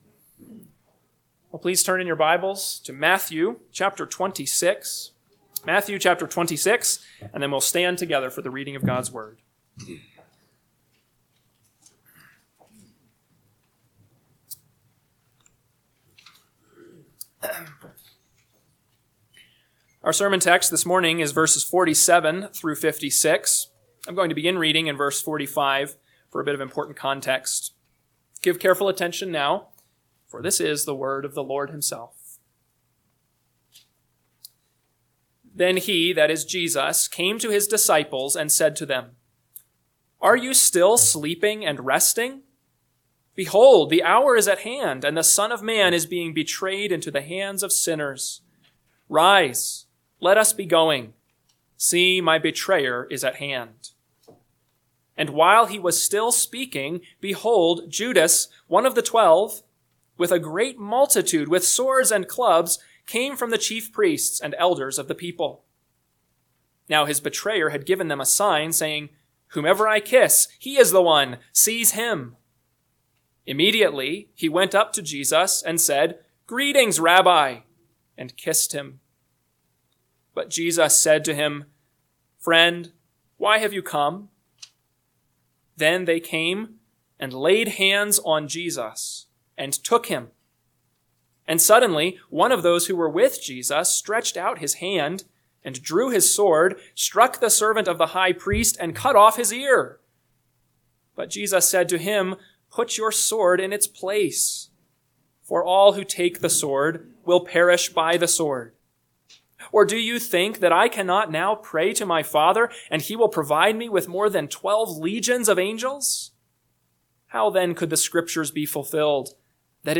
AM Sermon – 3/16/2025 – Matthew 26:47-56 – Northwoods Sermons